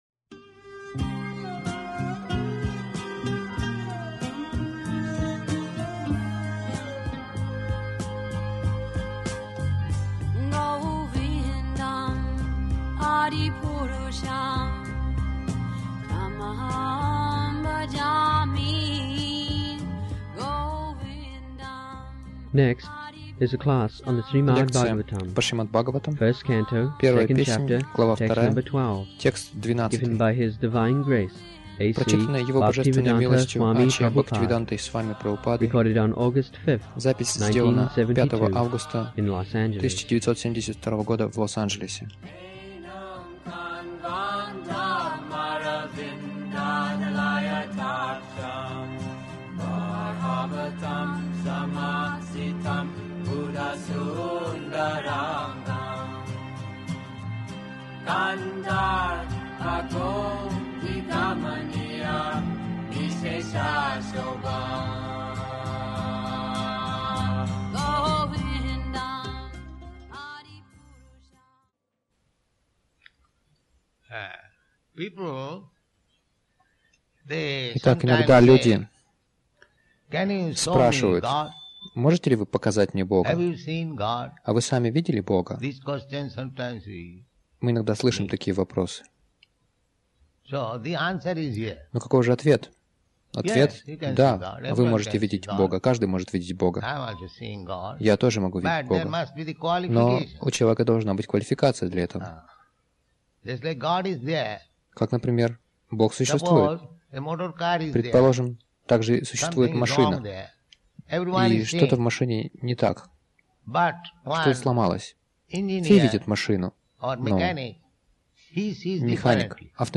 Милость Прабхупады Аудиолекции и книги 15.08.1972 Шримад Бхагаватам | Лос-Анджелес ШБ 01.02.12 Загрузка...